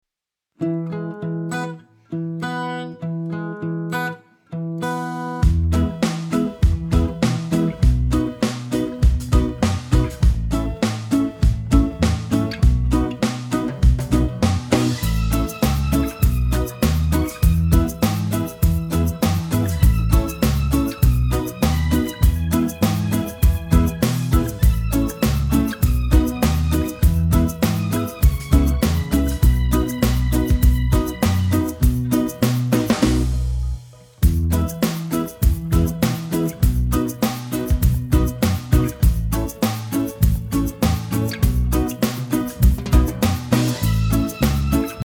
Listen to a sample of the sing-along track.
2. Mp3 Instrumental Sing Along track;